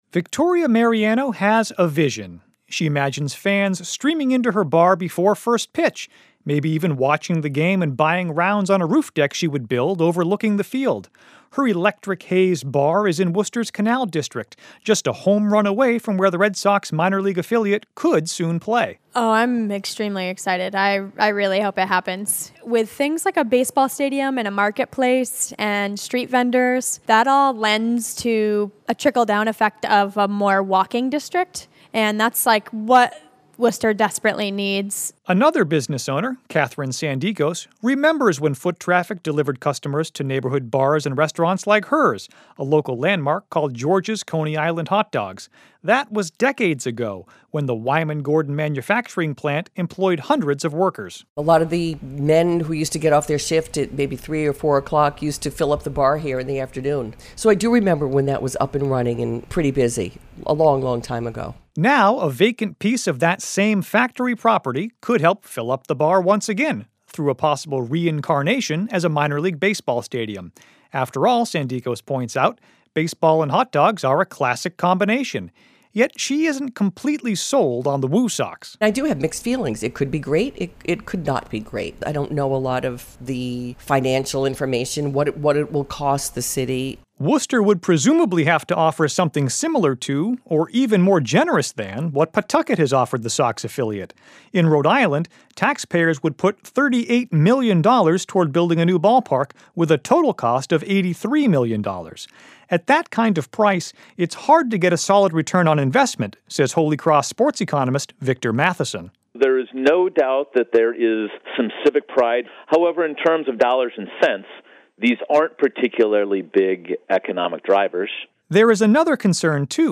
In Worcester, business owners expressed excitement and some trepidation about luring the PawSox to become the WooSox.